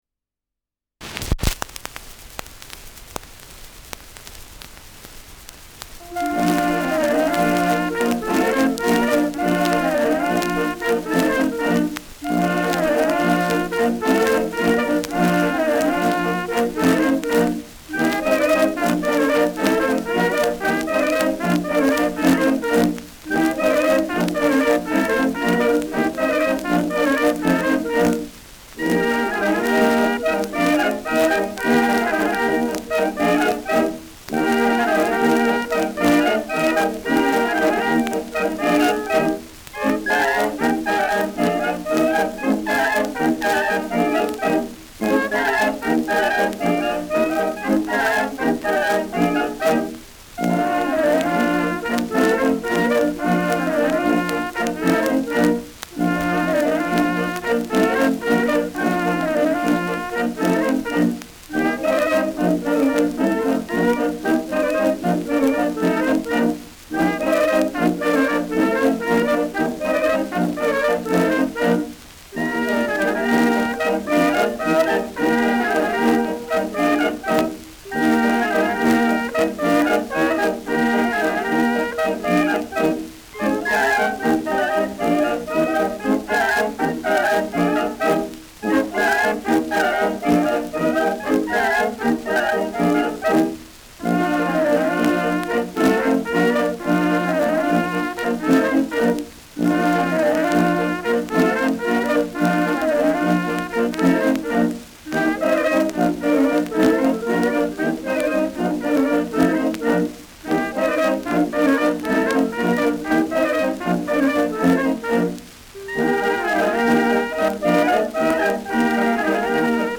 Schellackplatte
Leichtes Grundrauschen : Durchgehend stärkeres Knacken : Verzerrt leicht an lauteren Stellen
Dachauer Bauernkapelle (Interpretation)